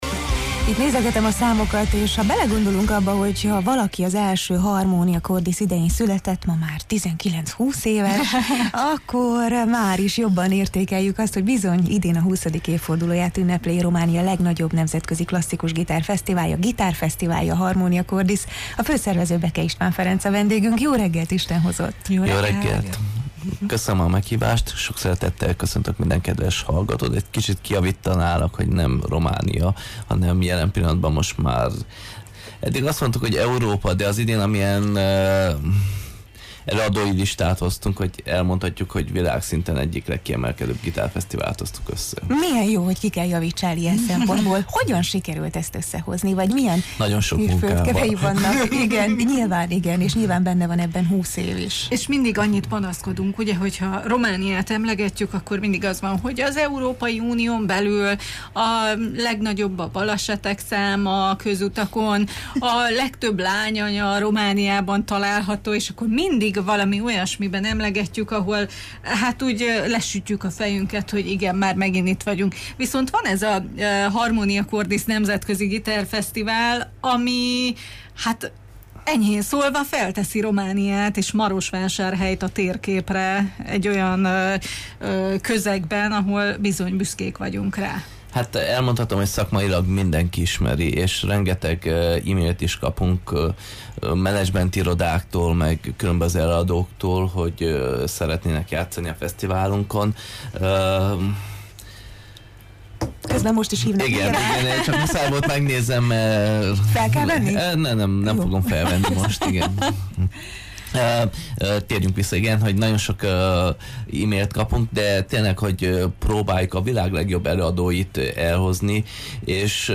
erről kérdeztük mai meghívottunkat